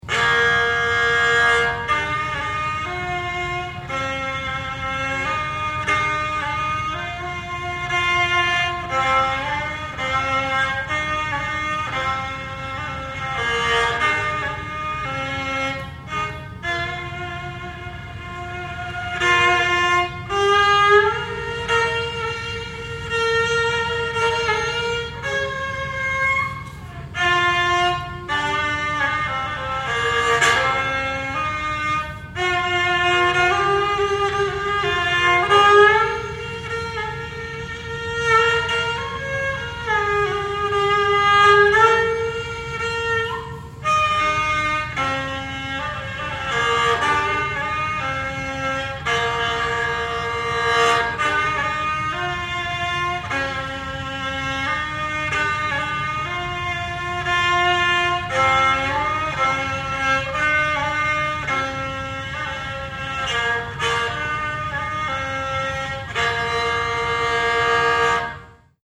Rebab
Rebab is a two-stringed bowed lute with a heart-shaped body of wood covered with a membrane made of parchment from a cow bladder.
Java, Indonesia
Bowed string
Javanese Gamelan